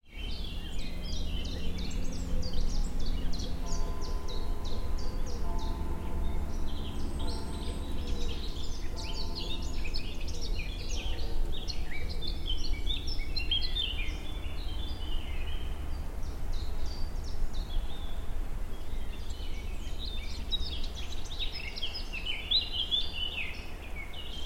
[صدای پرندگان و آب جاری]
صدای آواز پرندگان و رود جاری